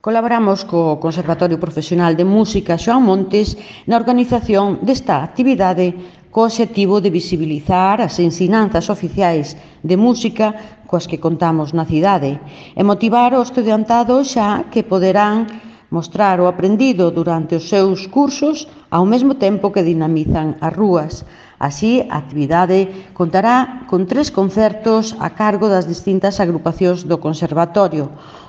Audio El edil de Cultura, Turismo y Promoción de la Lengua, Maite Ferreiro, sobre el Conservatorio de Música | Descargar mp3